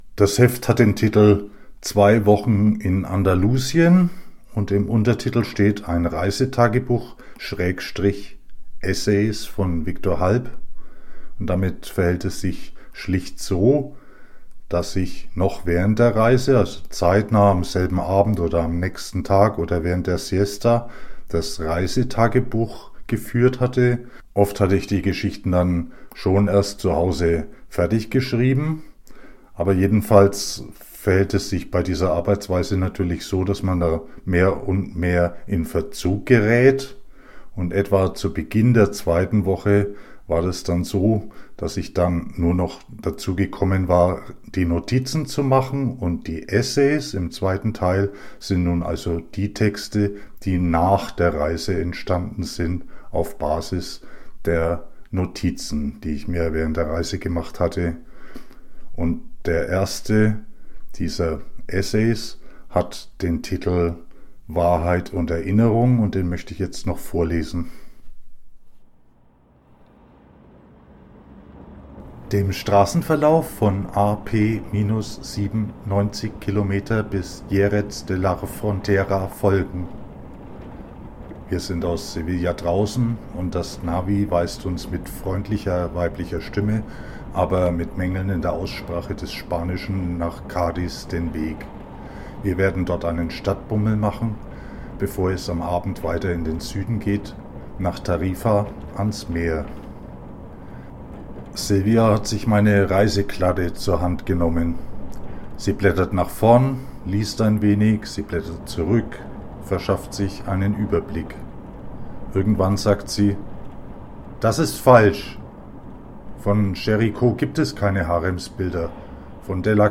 Reisetagebuch - Essays? Eine Hörprobe
Leseprobe (mit Hörspielelementen!): Der Essay „Wahrheit und Erinnerung“ (MP3; 11 min., 6 sec.).